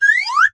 comedy_slide_whistle_up_001